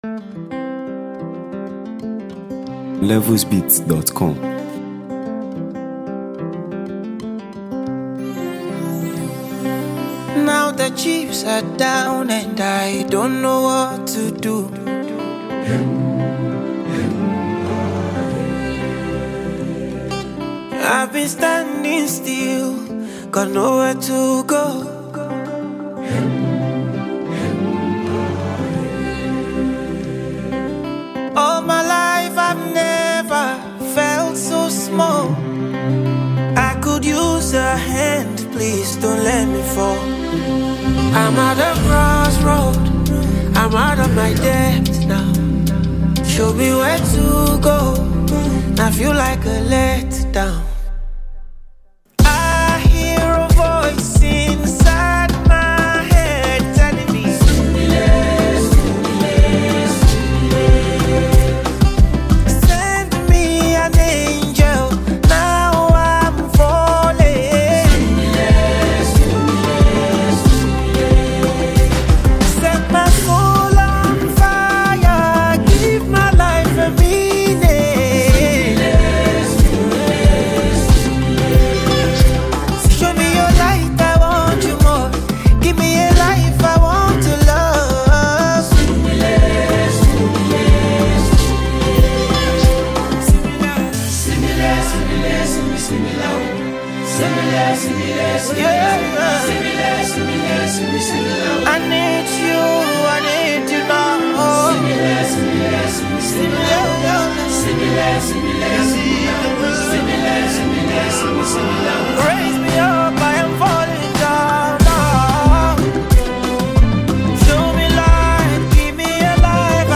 a soulful and engaging track
With its soothing rhythm and memorable hooks
Afrobeats music